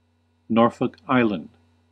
Ääntäminen
Ääntäminen US UK : IPA : /ˈnɔː.fək ˈaɪ.lənd/ US : IPA : /ˈnɔɹ.fɔk ˈaɪ.lənd/ Haettu sana löytyi näillä lähdekielillä: englanti Käännös Erisnimet 1.